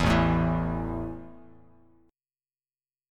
A5 Chord
Listen to A5 strummed